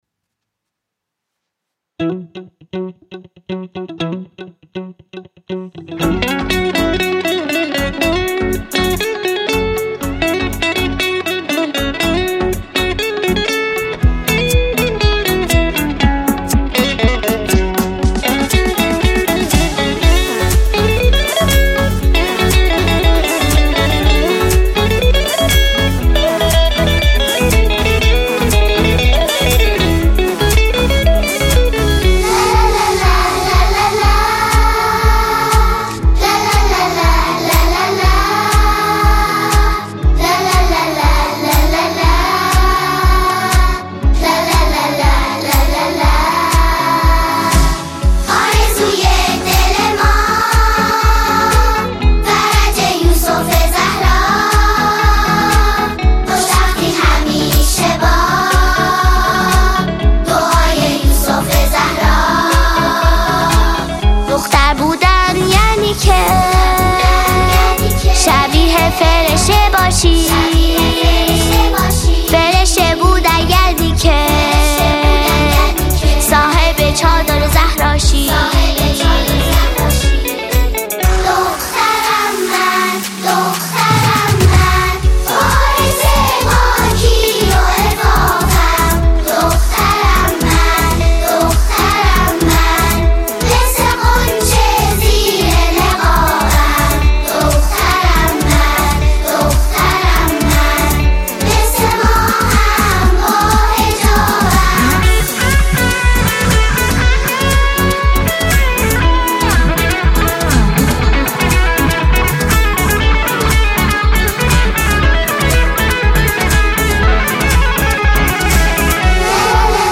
ژانر: سرود